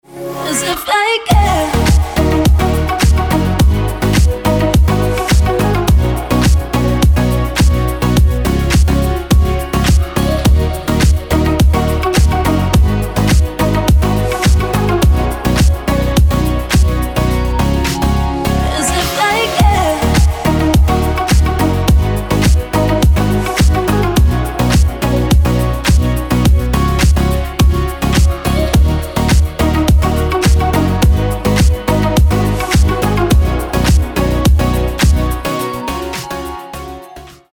• Качество: 320, Stereo
dance
house